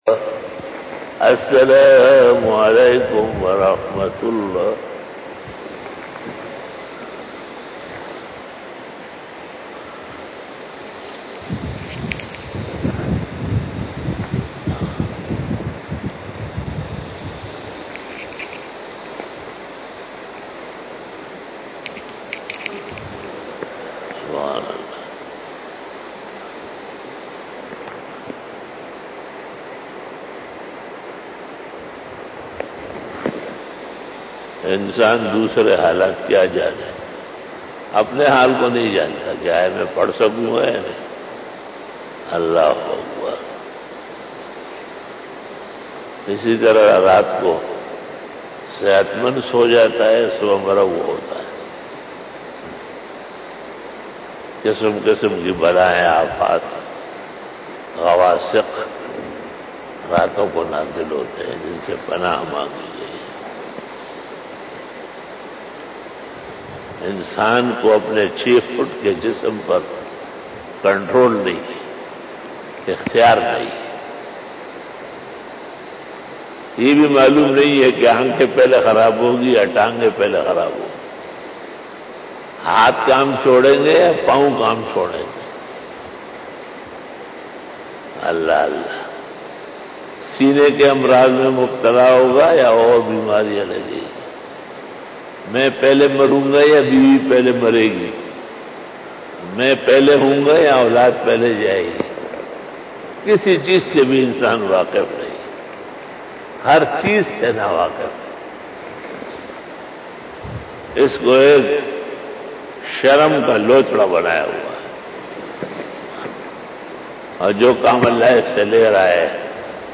Fajar Bayan 08 August 2020 (17 ZilHajj 1441HJ)